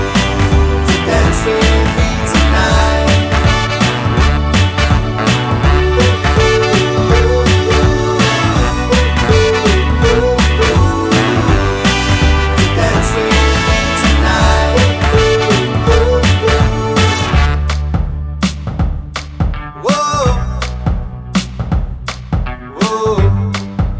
no intro BV Pop (2010s) 3:22 Buy £1.50